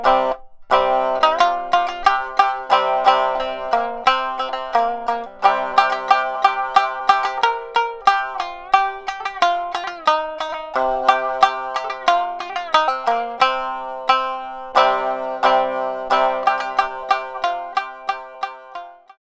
Traditional fishing folk song (Minyo) for shamisen.
• niagari tuning (C-G-C)